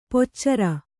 ♪ poccara